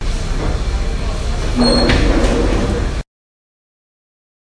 门 " 门铰链02
描述：门铰链吱吱声打开然后关闭。没有门碰到框架的声音。
Tag: 关闭 打开 吱吱 铰链